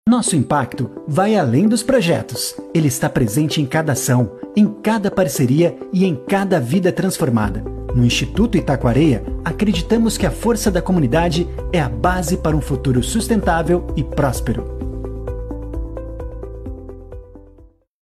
Animada